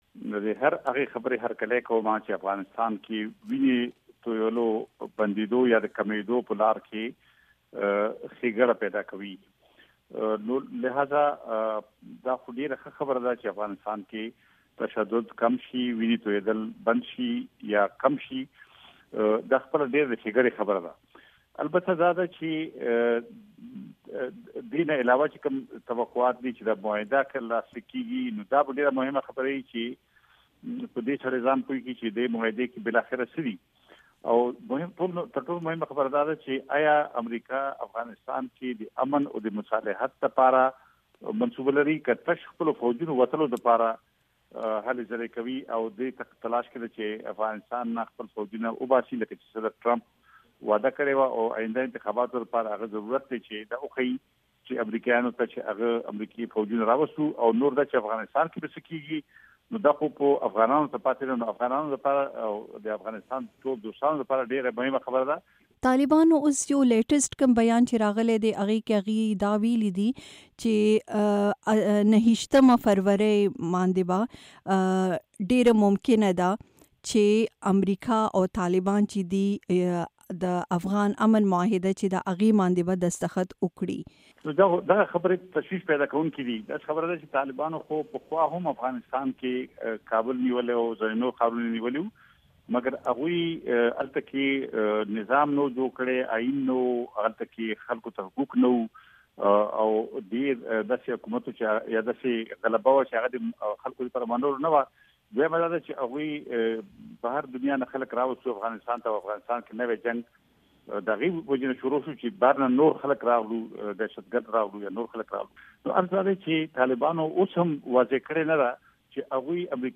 د تاوتریخوالي کمیدو په اړه له سیاسي کارپوه افراسیاب خټک سره مرکه